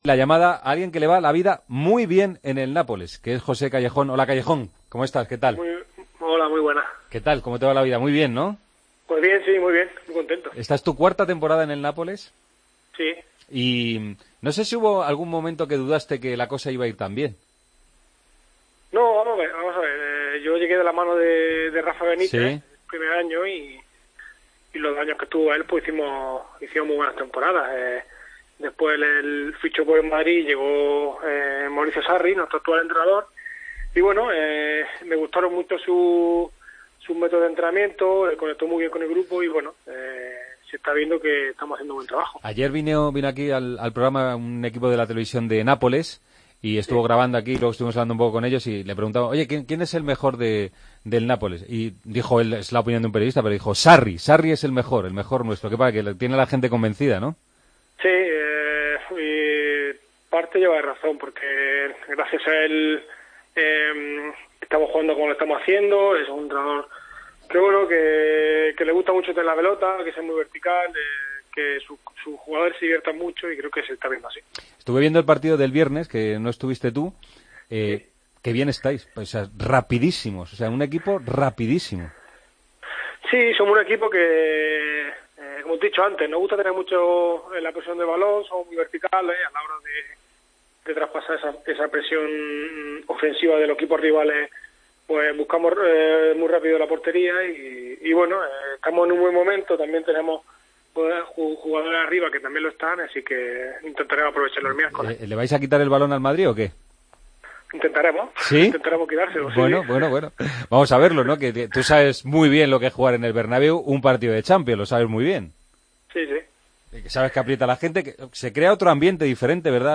Hablamos con uno de los jugadores del Nápoles, el día antes del enfrentamiento contra el Real Madrid: "Me gusta mucho los métodos de Sarri y encajó bien en el grupo.